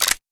select.wav